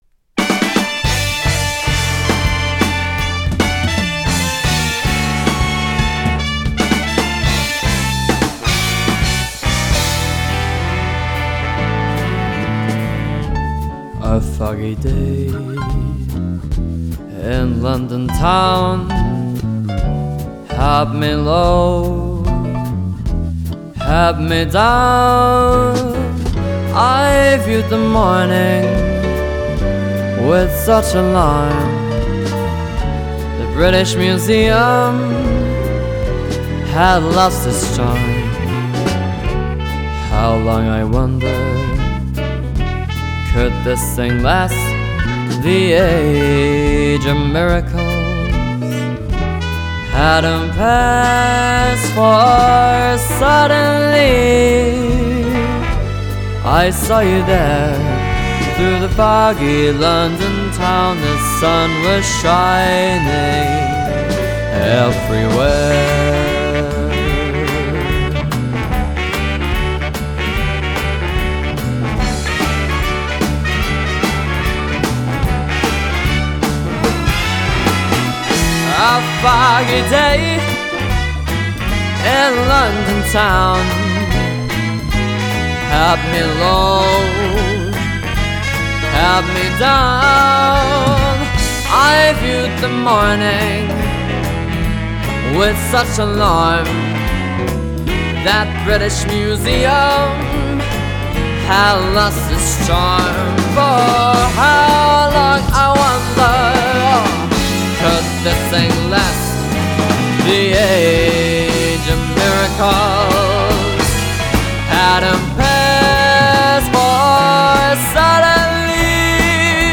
A Musician, Singer/Pianist with Talent Beyond His Years